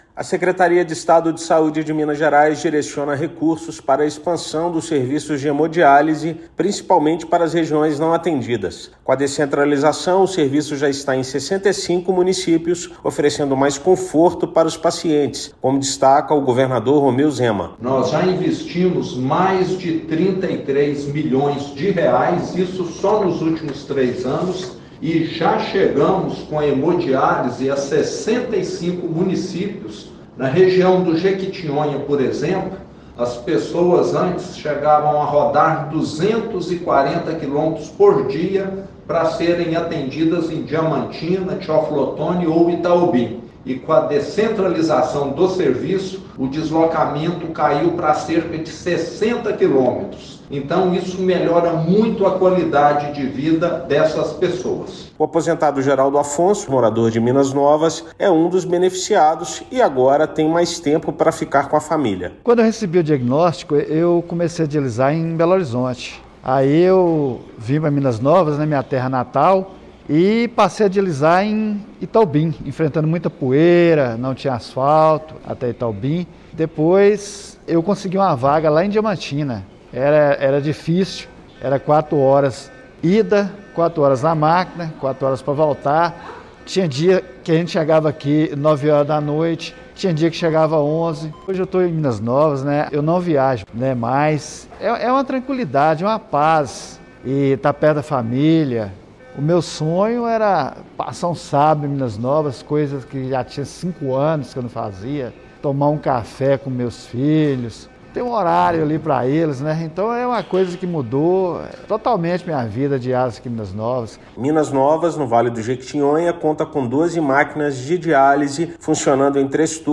Habilitação de novos serviços e aumento da capacidade dos já existentes reduzem tempo de deslocamento e trazem qualidade de vida para pacientes. Ouça matéria de rádio.